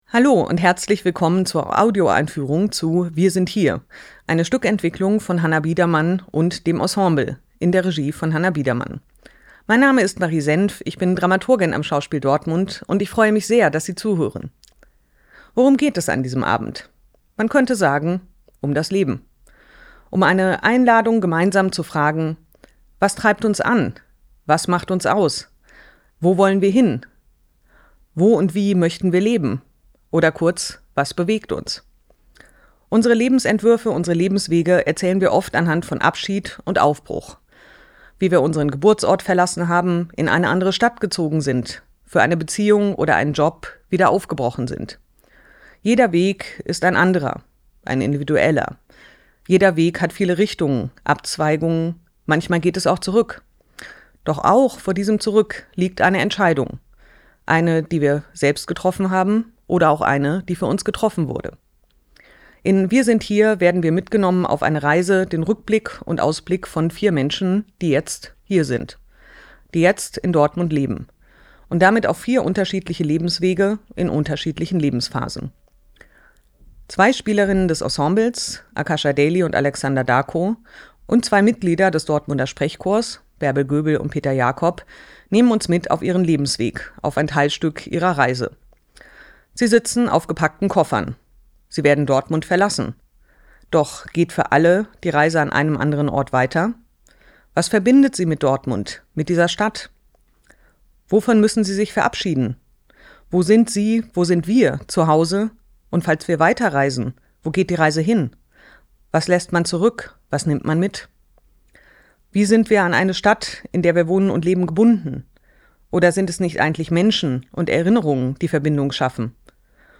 tdo_Einfuehrung_WirSindHier.mp3